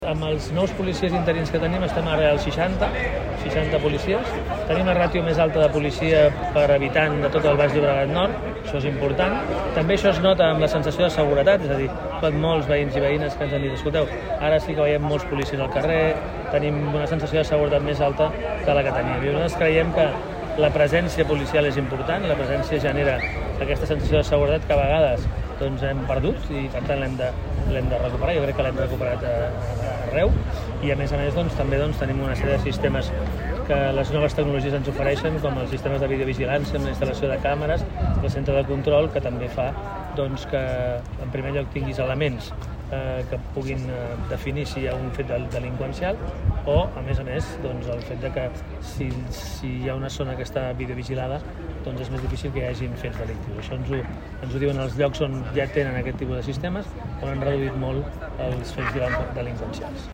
Xavier Fonollosa, alcalde de Martorell